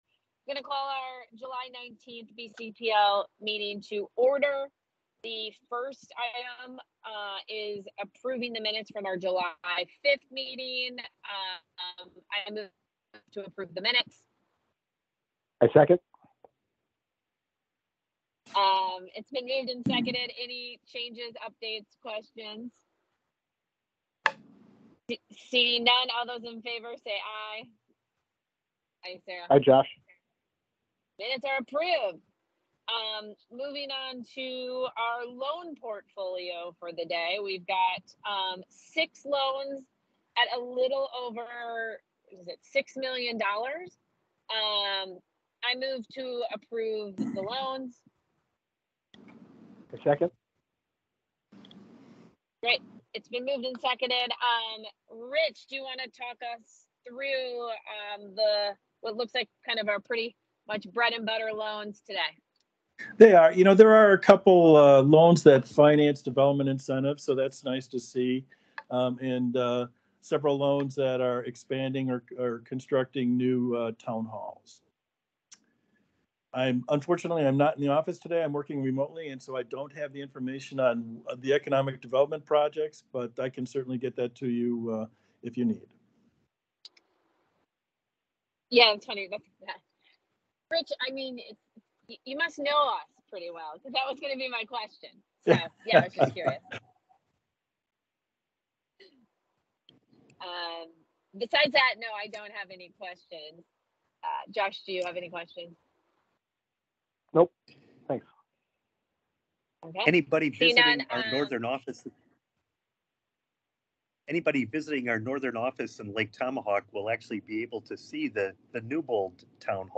Listen to first January meeting recording